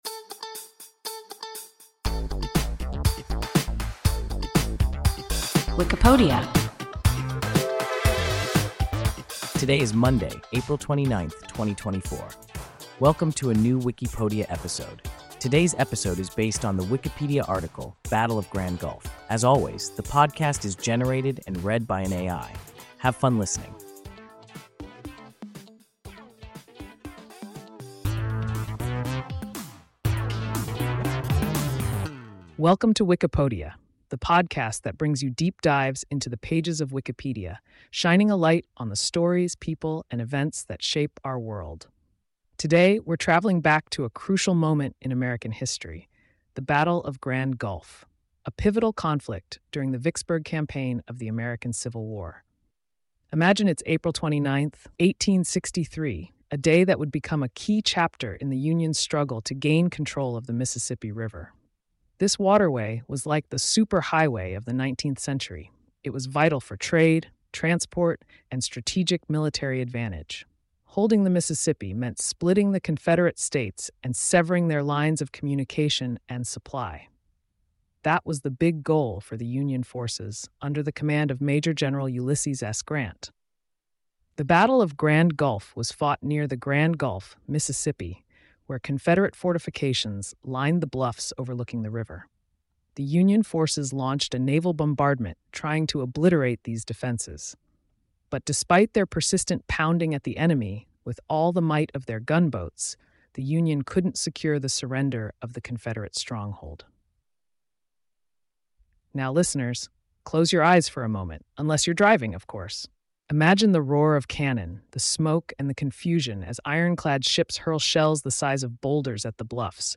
Battle of Grand Gulf – WIKIPODIA – ein KI Podcast